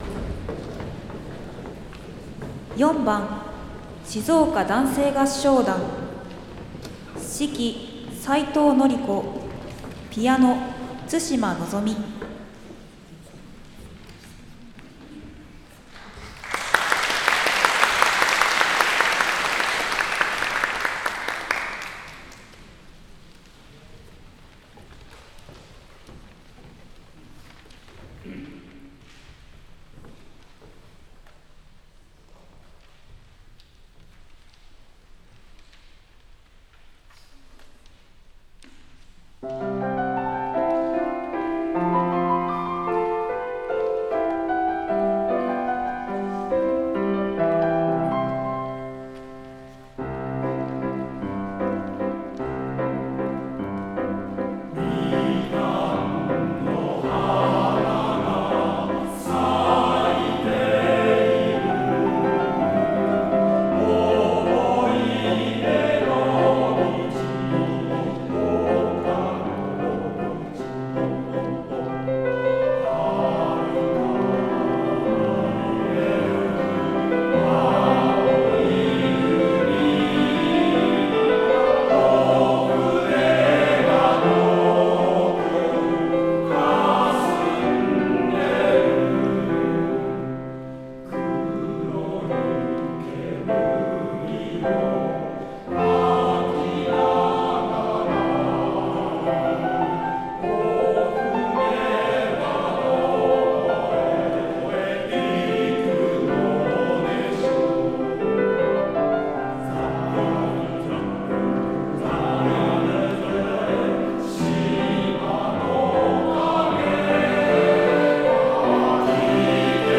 6/8 第88回合唱のつどい